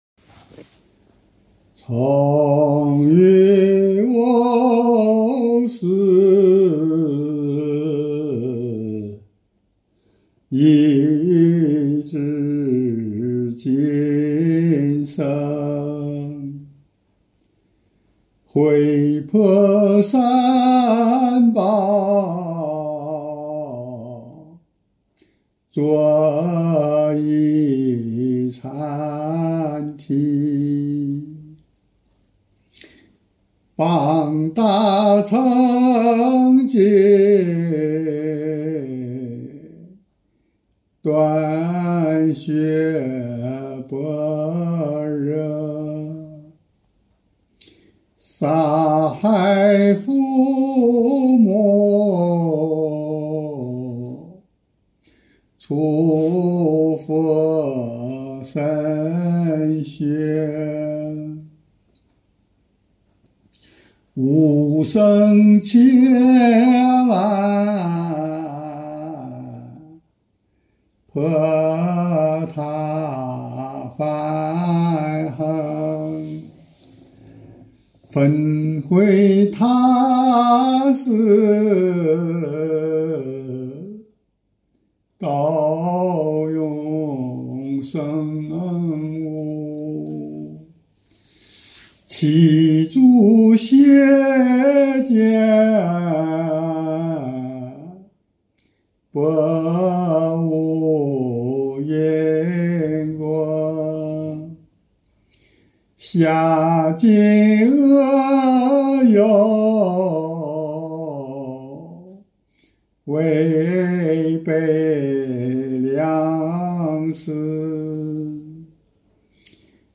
附录师父唱诵 ‘ 忏悔文 ’   忏悔偈 ’
其中的哭腔，是自己的悲心生起，不由自主地真心流露……真正的滴血流泪！